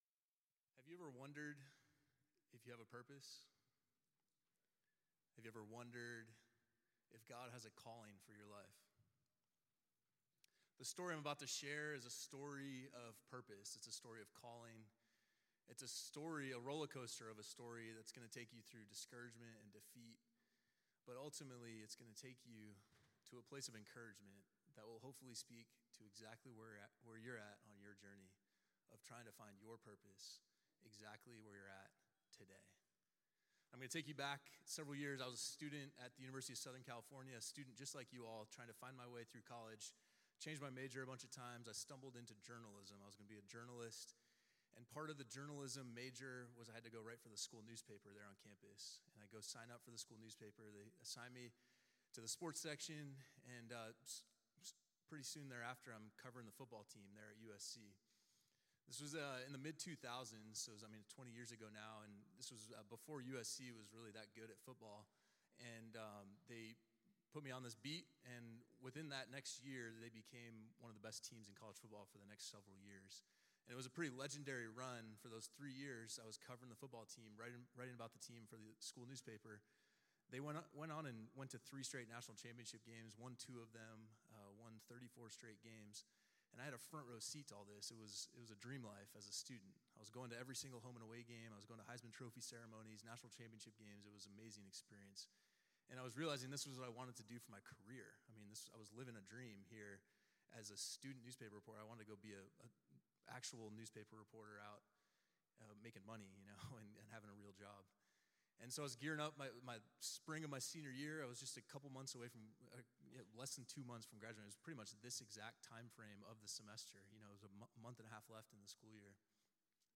This talk given in chapel on Wednesday, April 2nd, 2025.